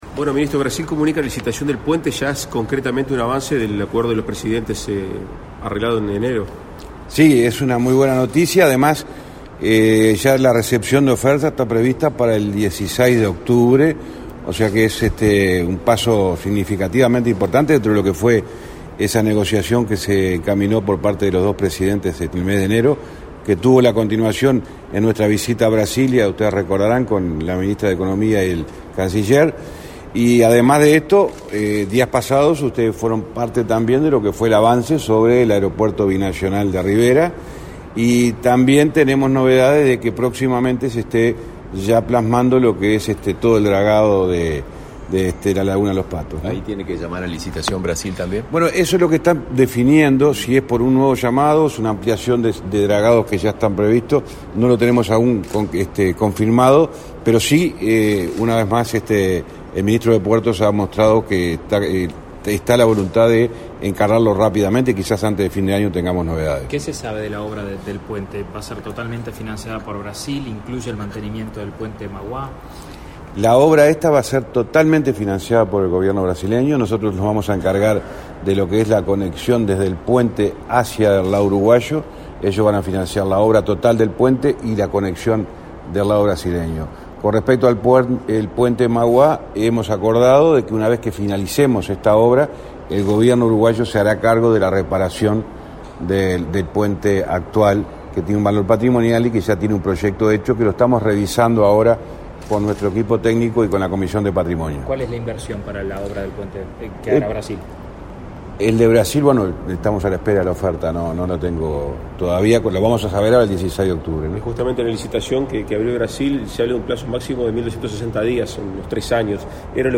Declaraciones a la prensa del titular del MTOP, José Luis Falero